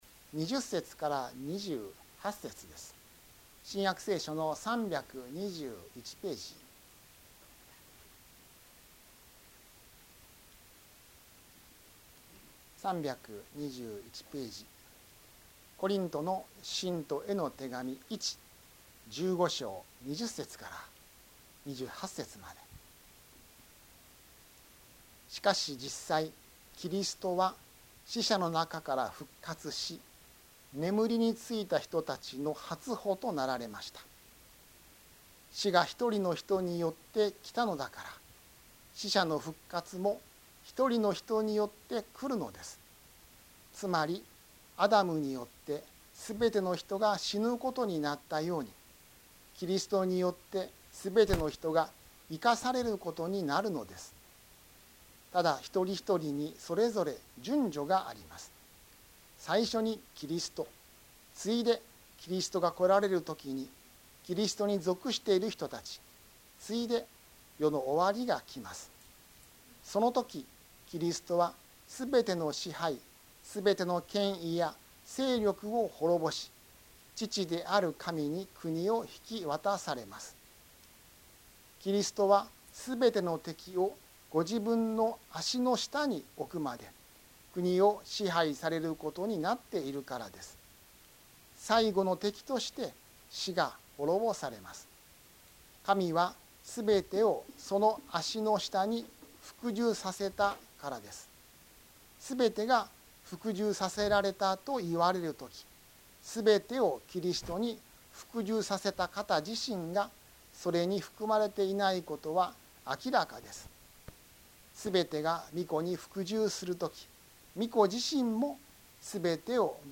宝塚の教会。説教アーカイブ。